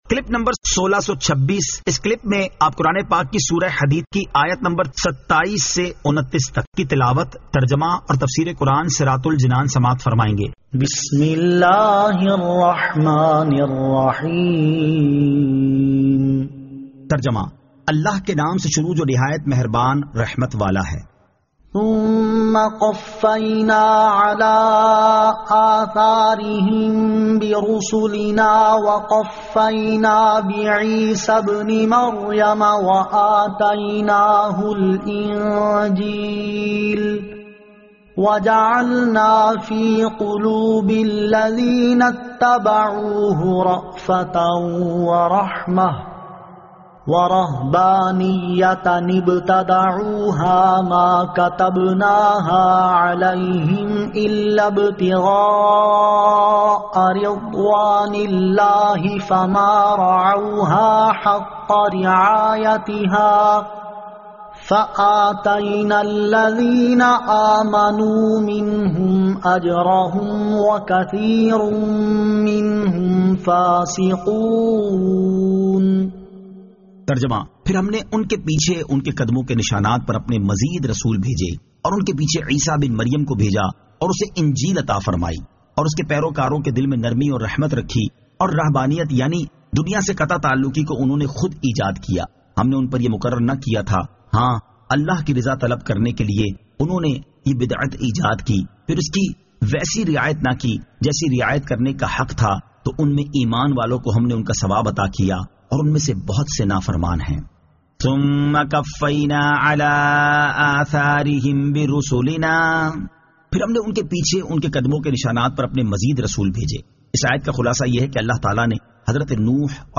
Surah Al-Hadid 27 To 29 Tilawat , Tarjama , Tafseer